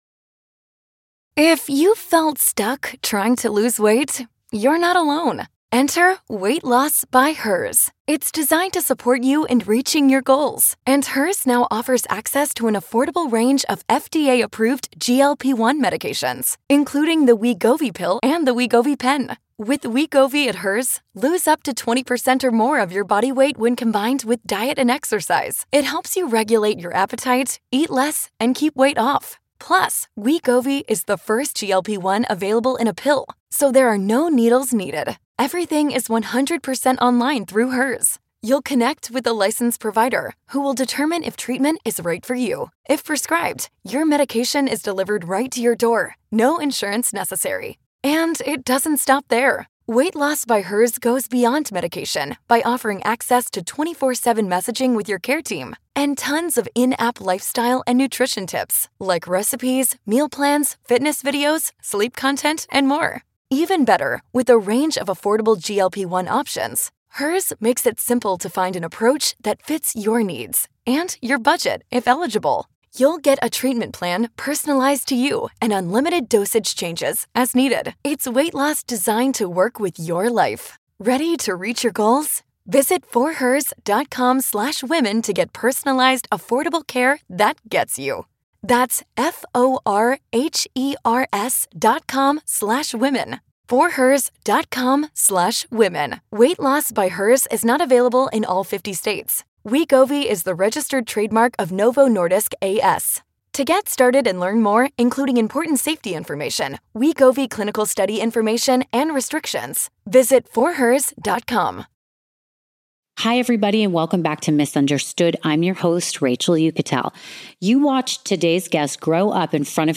After Baywatch: Moment in the Sun" features dozens of new cast interviews and never-before-seen footage, exploring the show that became the pop culture ambassador for the American dream We revisit our interview from August 17th, 2023 with Jeremy Jackson who discusses how he got cast,